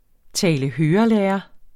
Udtale [ ˌtæːləˈhøːʌˌlεːʌ ]